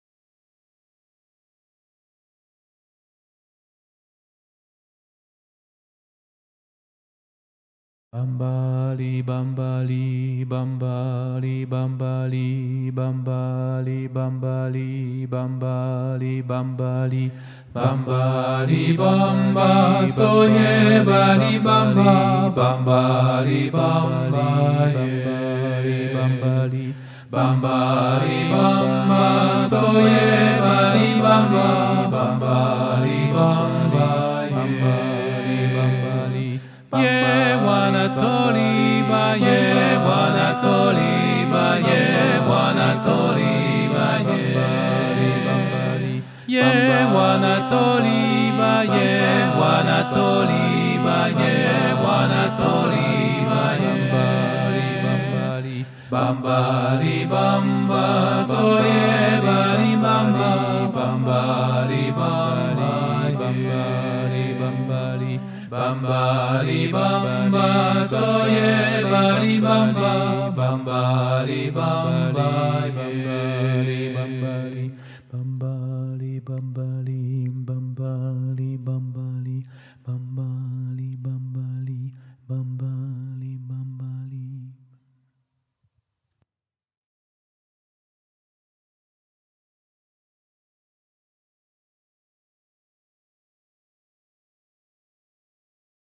- Chant traditionnel du Sénégal
MP3 versions chantées
Tutti